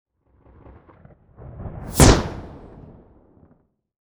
MAGIC_SPELL_Build_Bang_mono.wav